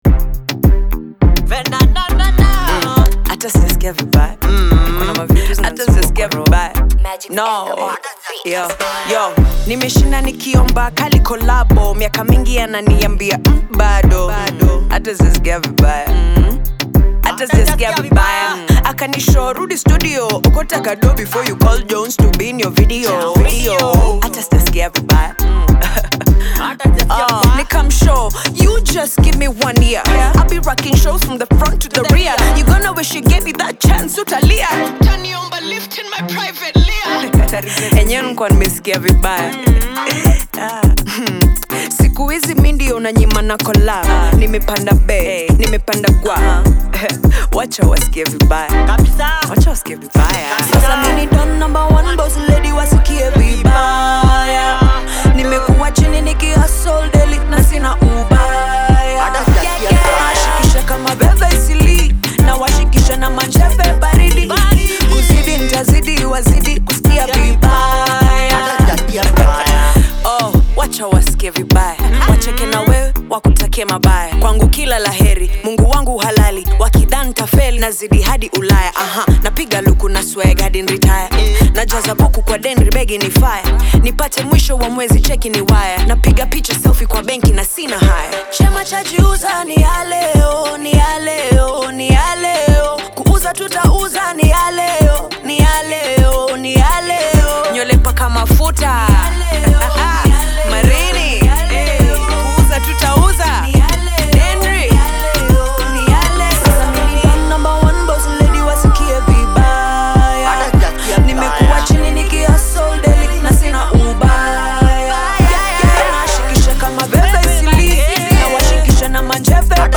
The upbeat club banger